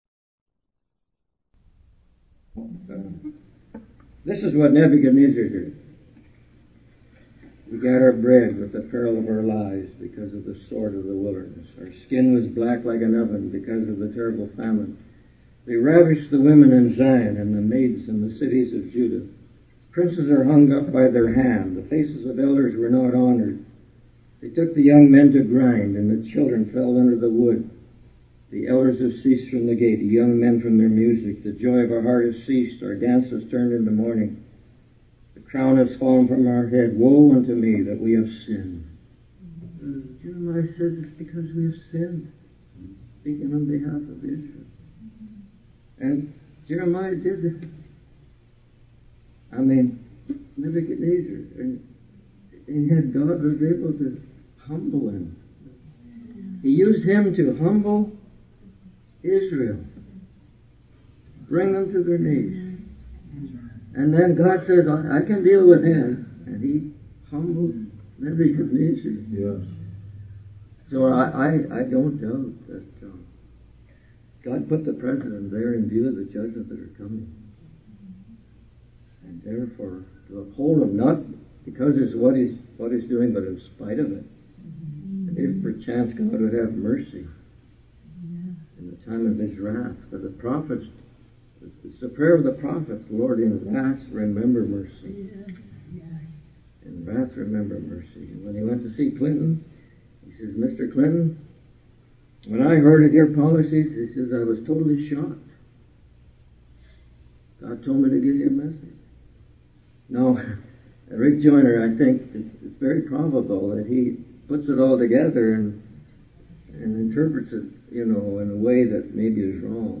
In this sermon, the preacher emphasizes the importance of following God's instructions and having communion with Him.